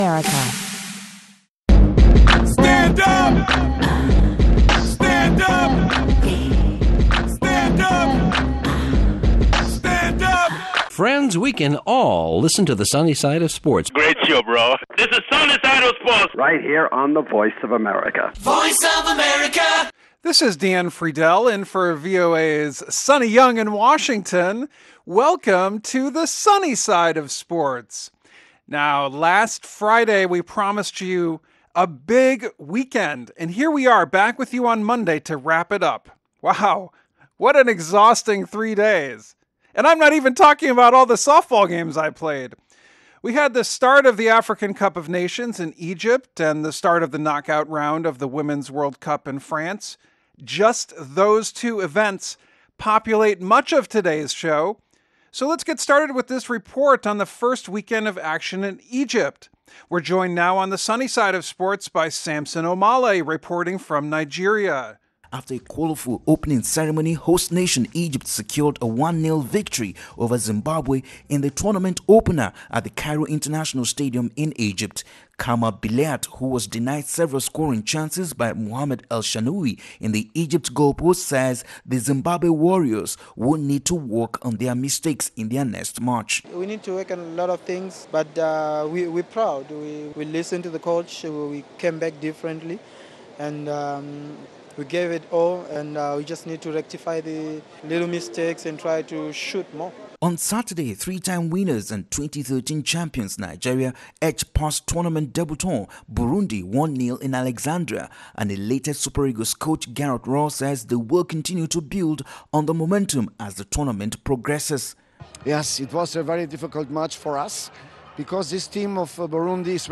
a lively presentation that combines humor, props, sound effects and correspondent reports from Africa and all over the...